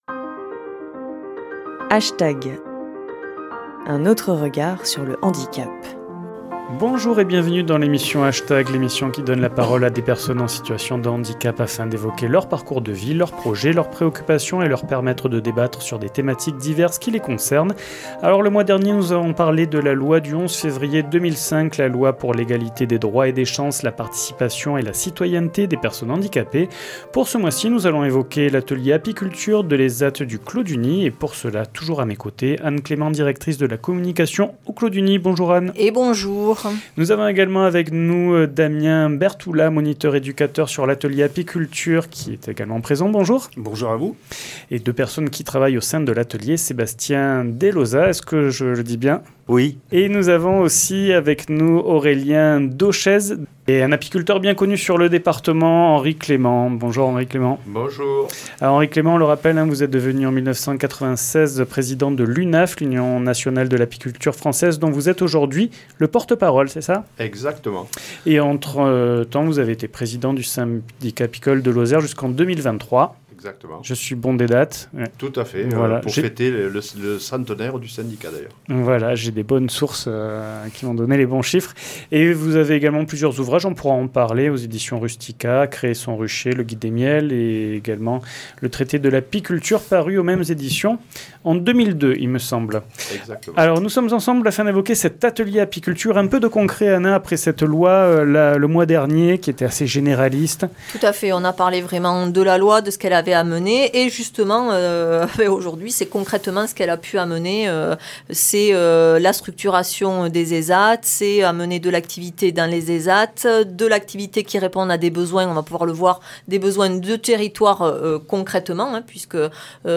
H Tag ! donne la parole à des personnes en situation de handicap ou des personnes en situation de vulnérabilité afin d’évoquer leurs parcours de vie, leurs projets, leurs préoccupations, et leur permettre de débattre sur des thématiques diverses qui les concerne